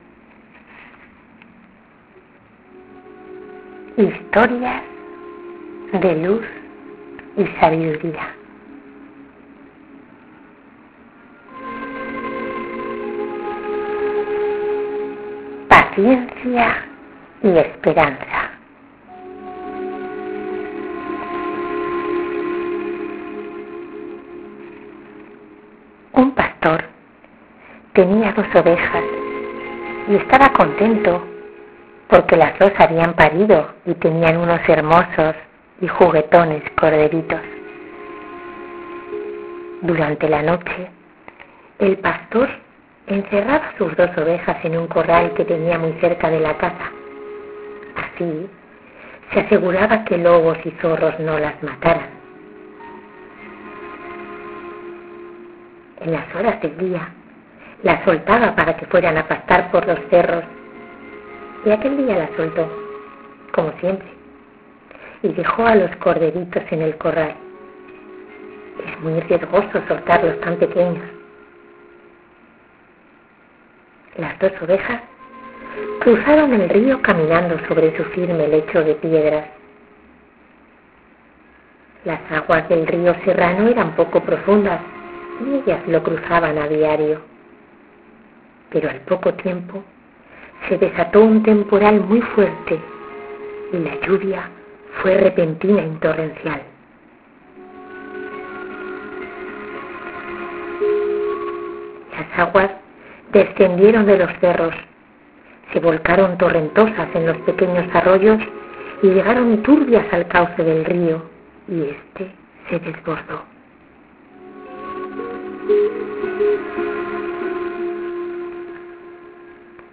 historia narrada